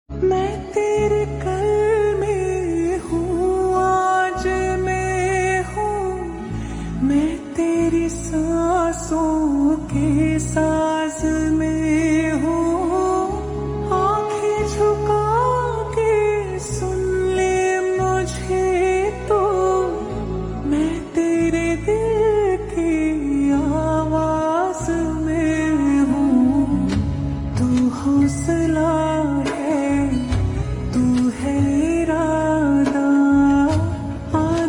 Slow Reverb Version
• Simple and Lofi sound
• Crisp and clear sound